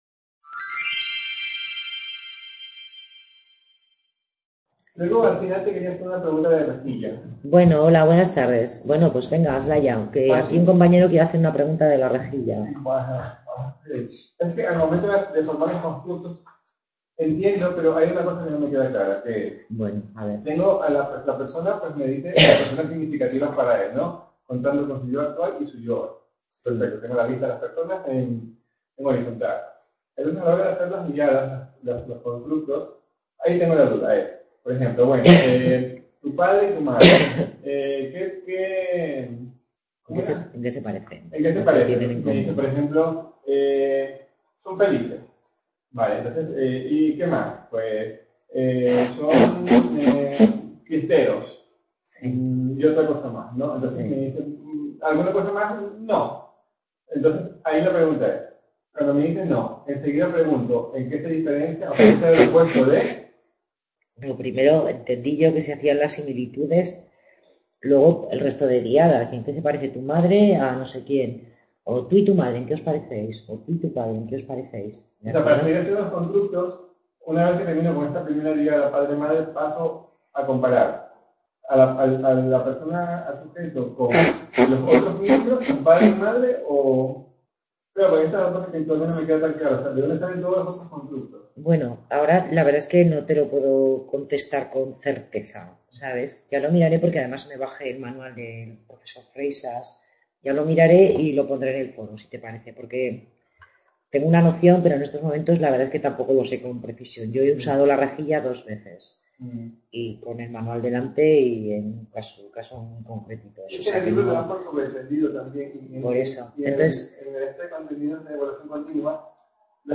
Tutoría grupal sobre el tema de Evaluación Psicofisiológica